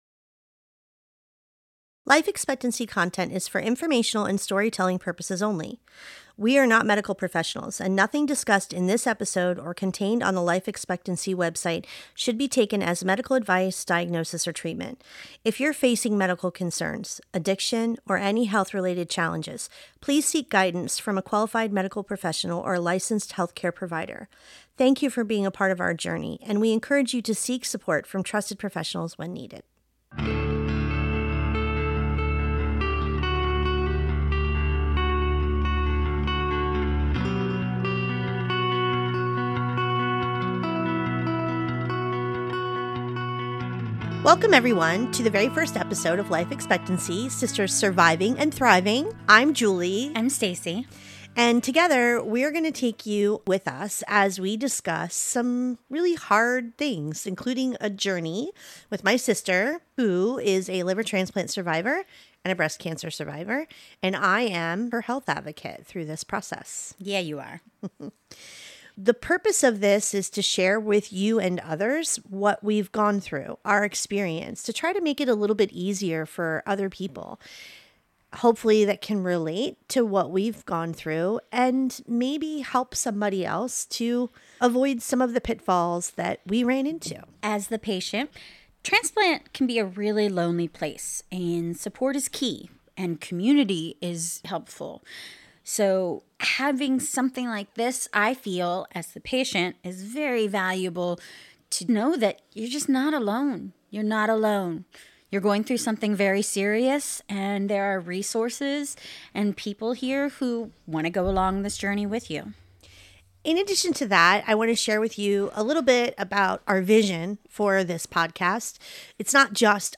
In this raw and revealing conversation, they introduce the heart behind the podcast: building a space where survivors, caregivers, and families can find solidarity, support, and useful tools for navigating some of life’s most ter